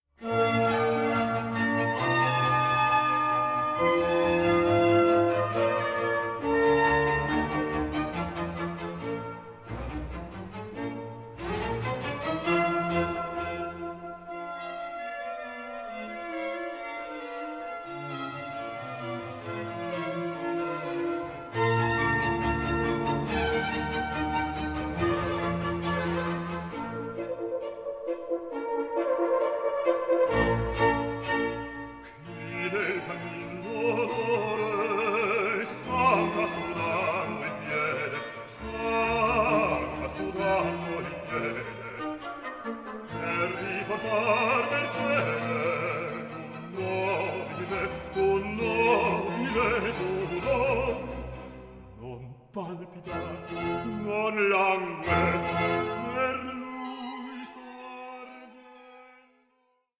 EXOTIC OPERA WITH AN ELABORATE SCORE
play this lavish score with fervour and swing
offer an equally virtuosic vocal delivery.